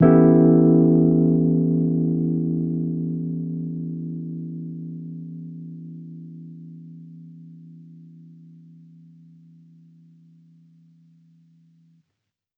Index of /musicradar/jazz-keys-samples/Chord Hits/Electric Piano 1
JK_ElPiano1_Chord-Em13.wav